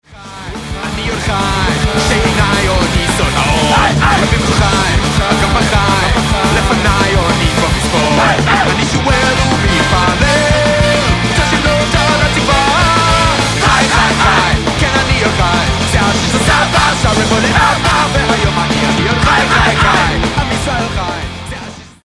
A very cool, in-your-face quick, thrashy punk EP.